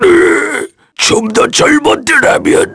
Ricardo-Vox_Dead_kr.wav